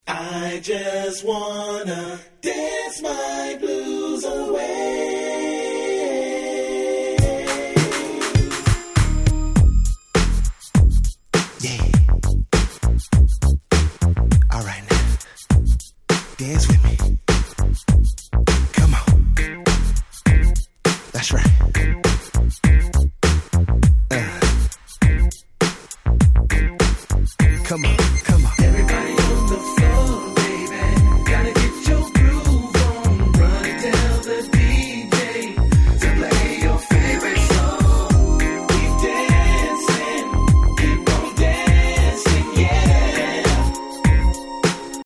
Very Smooth♪